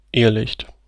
"Irrlicht" pronunciation :D.
Ok I recorded me saying "irrlicht".